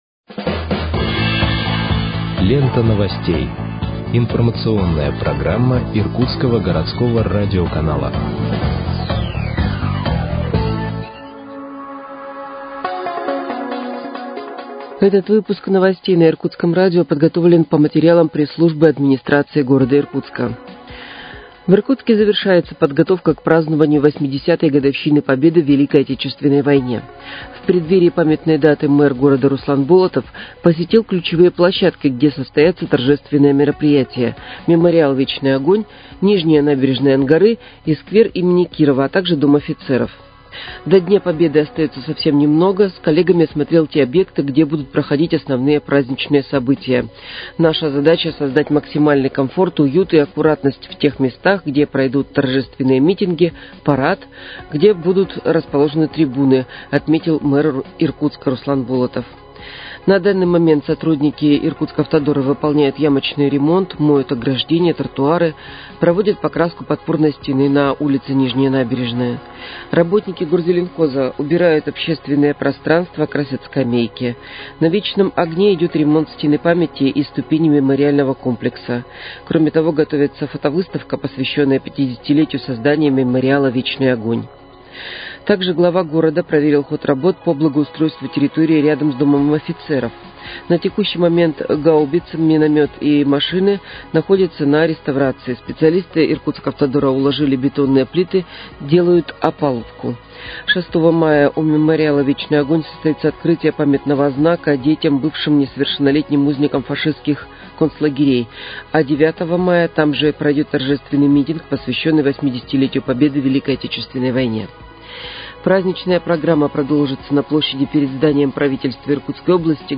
Выпуск новостей в подкастах газеты «Иркутск» от 05.05.2025 № 1